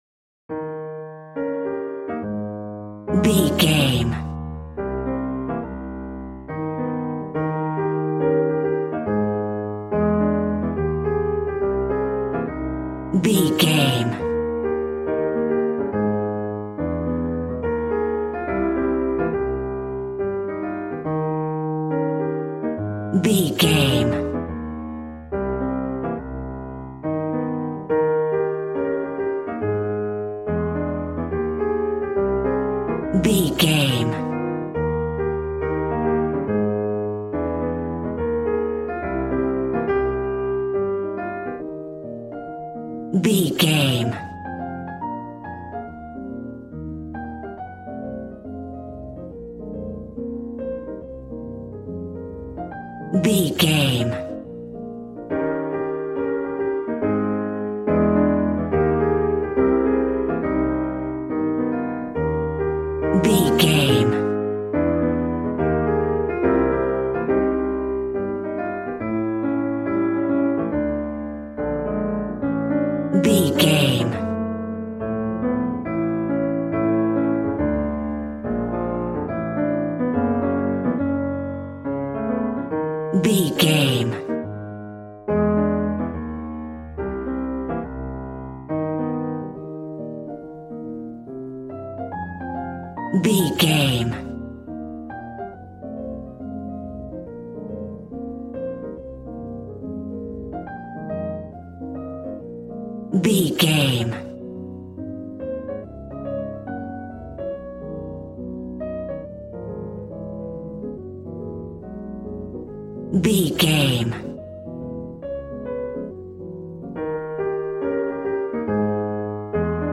Smooth jazz piano mixed with jazz bass and cool jazz drums.,
Ionian/Major
E♭